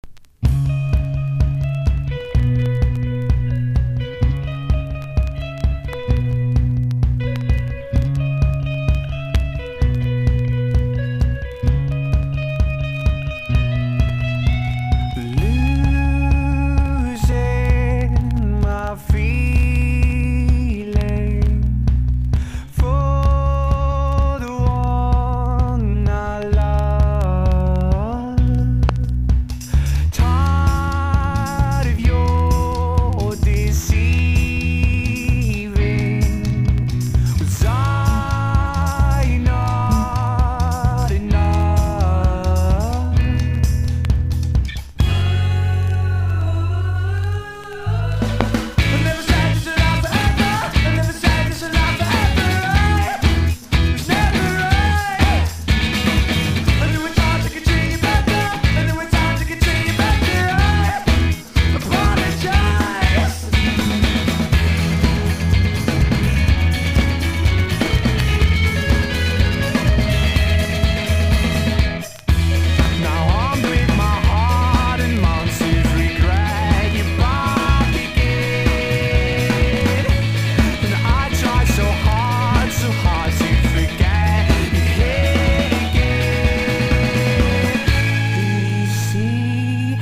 # INDIE DANCE
NEO ACOUSTIC / GUITAR POP (90-20’s)
トロピカルに盛り上がる後半まで息を着かせぬ熱い展開にやらせます！